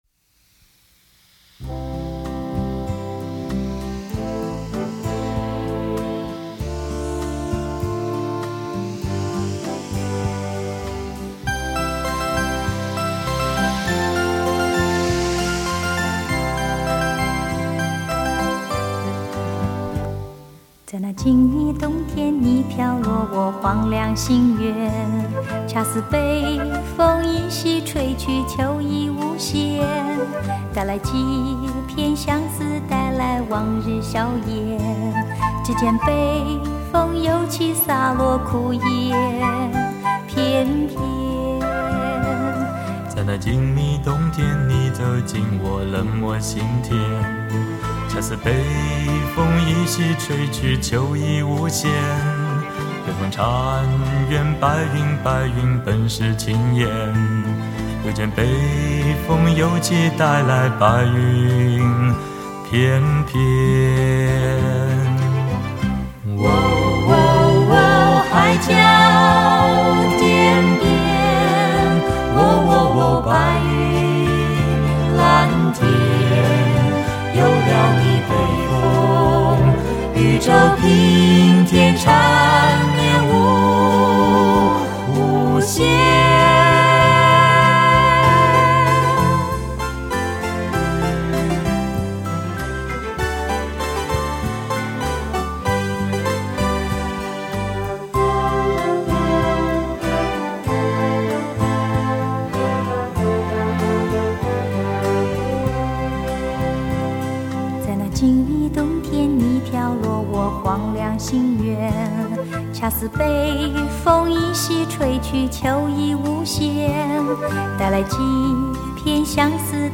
经由男女声部的组合变化